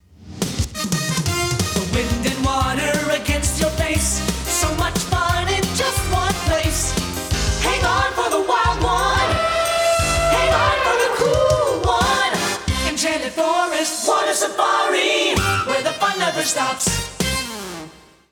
Jingle Lyrics: